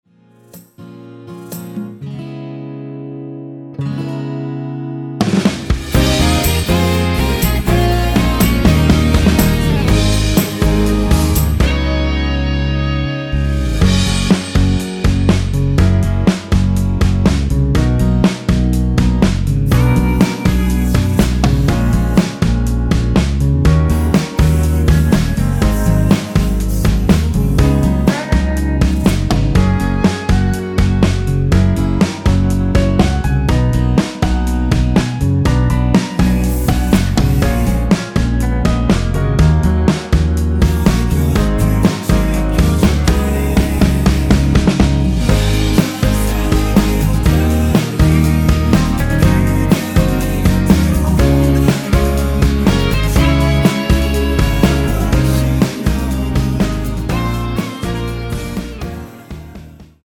원키에서(-1)내린 코러스 포함된 MR입니다.(미리듣기 확인)
전주없이 노래가 시작 되는 곡이라 전주 1마디 만들어 놓았습니다.
Gb
앞부분30초, 뒷부분30초씩 편집해서 올려 드리고 있습니다.